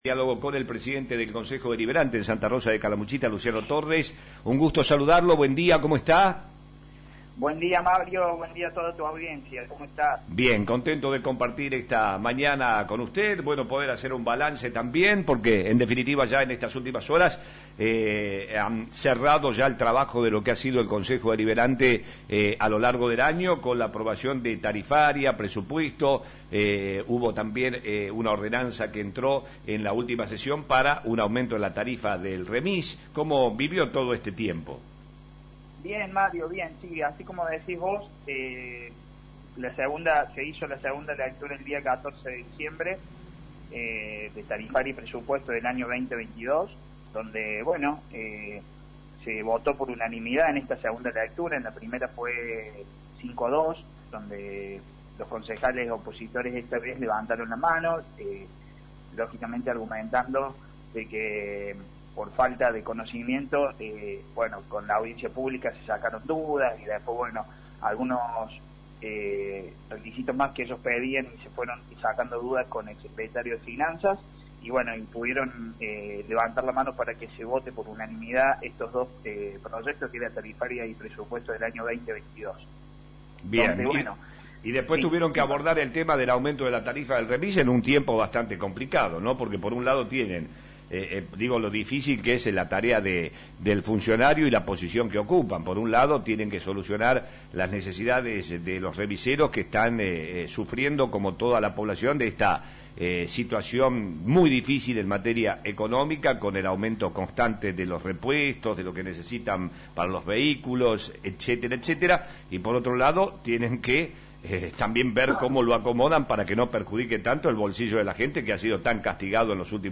Lo confirmó en diálogo con Flash FM el titular del Cuerpo, Luciano Torres quien resaltó el trabajo realizado durante todo el año y la buena relación con la oposición.